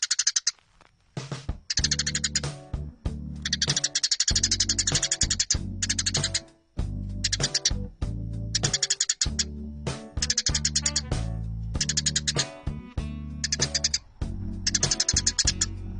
树麻雀叫声